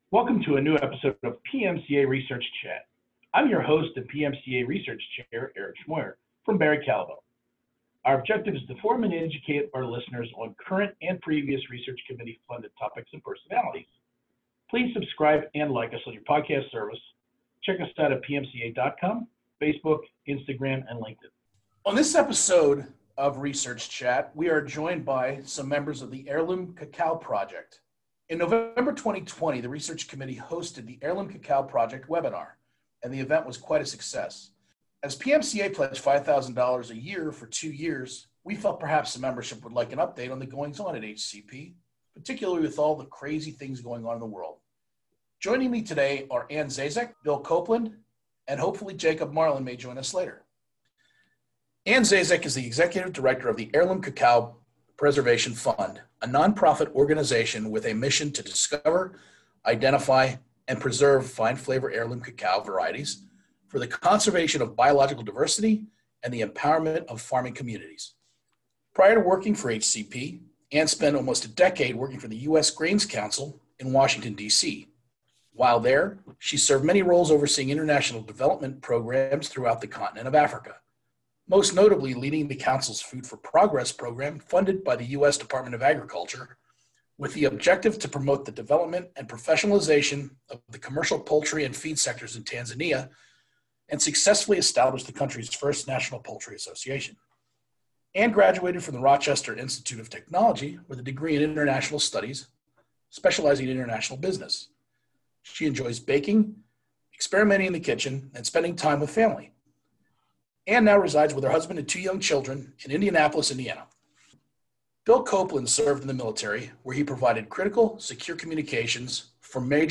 Episode16-HCP-Roundtable.mp3